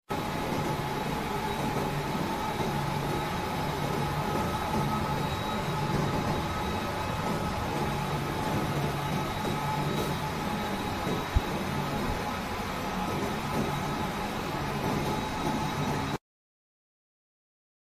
network engineer pov: enjoy the noise of cisco core switch as muisc